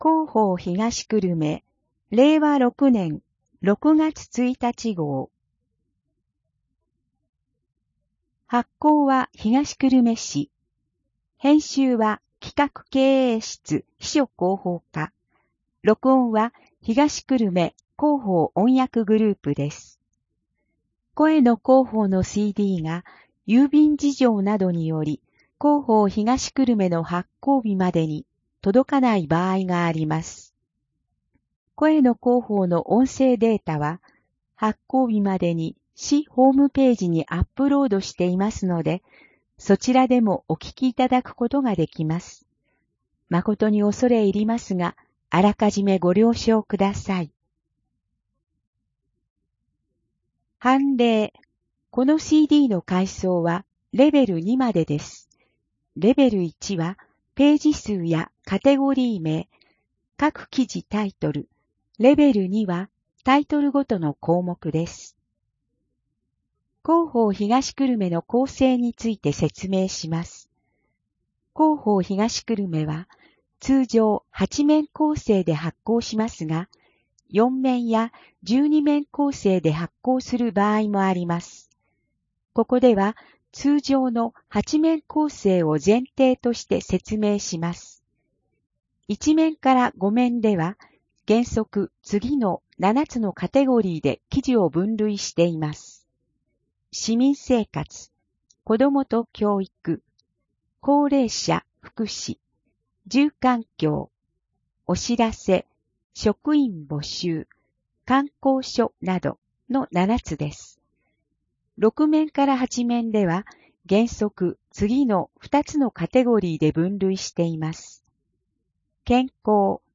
声の広報（令和6年6月1日号）